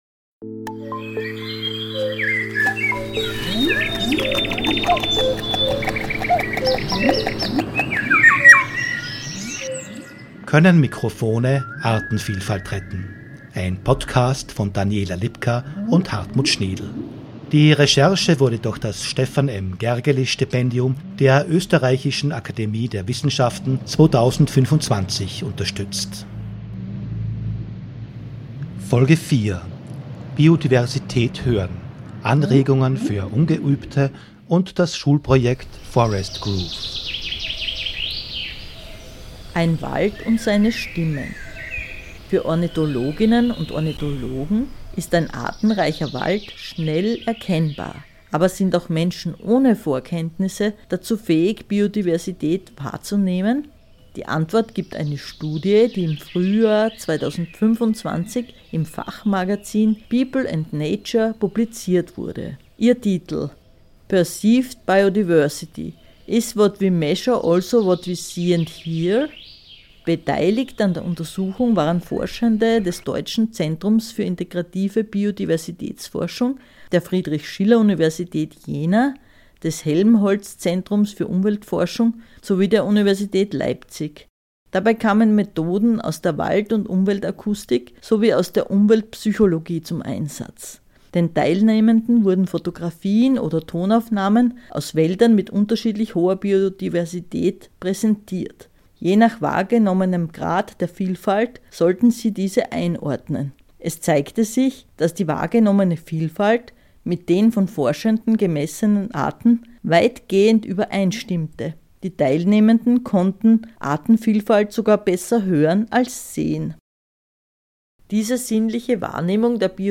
Tierlaute-Aufnahmen